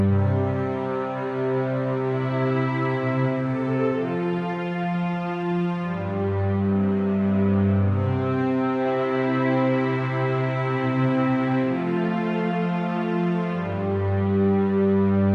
描述：主音是C和G。 Whateva。
Tag: 125 bpm Cinematic Loops Strings Loops 2.58 MB wav Key : Unknown